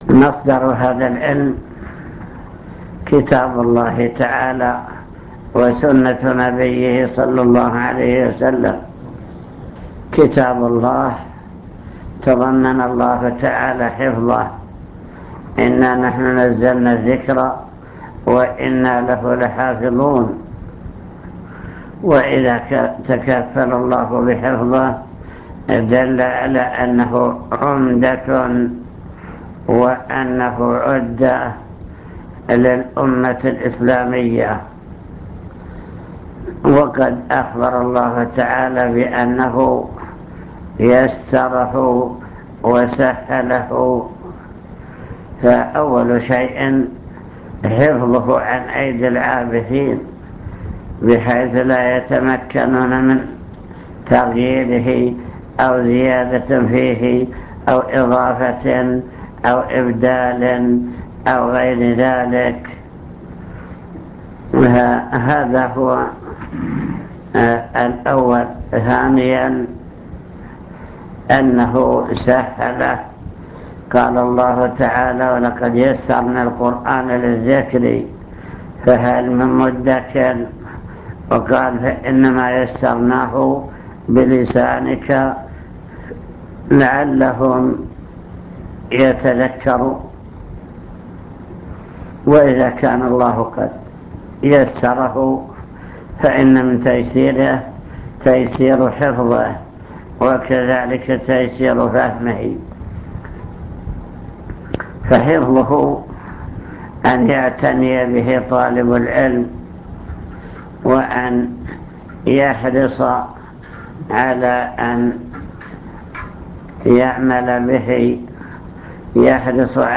المكتبة الصوتية  تسجيلات - لقاءات  لقاء في عنك